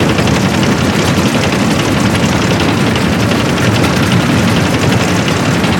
track_move.ogg